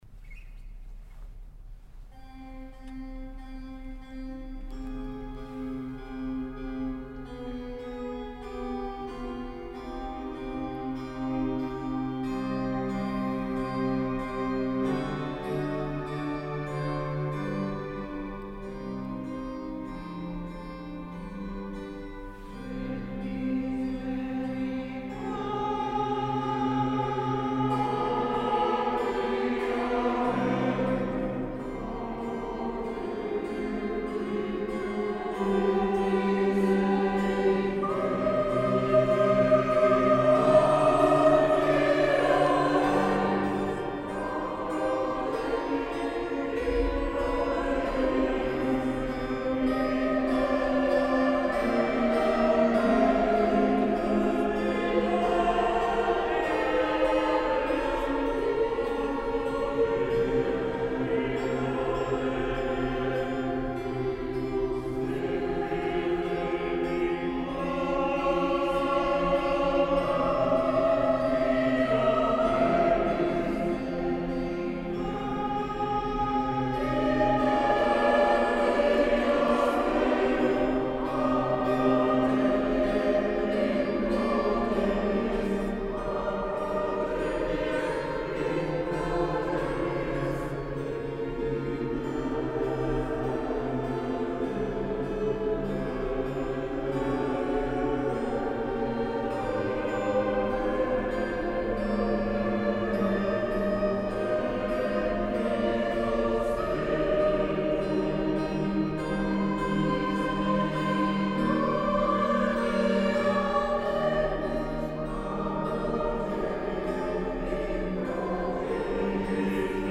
S. Gaudenzio church choir Gambolo' (PV) Italy
Domenica 9 dicembre ore 21.00    Chiesa di San Pietro Martire   Vigevano